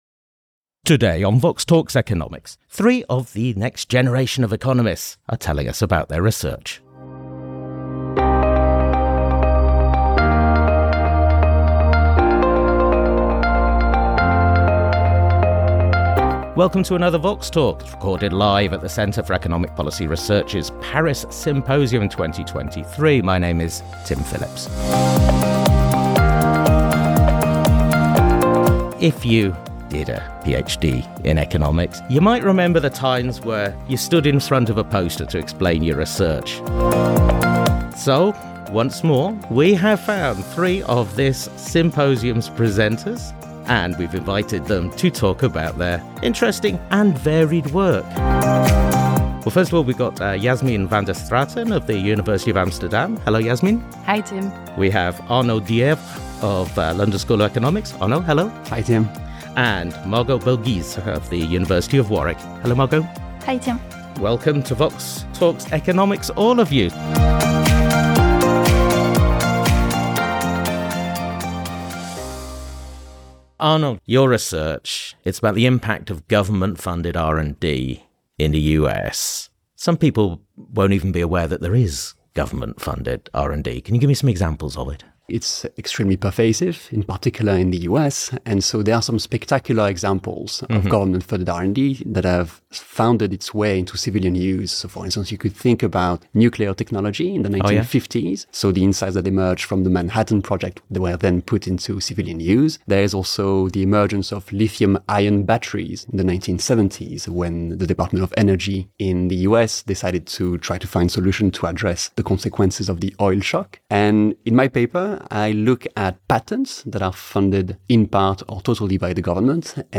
Recorded at CEPR Paris Symposium 2023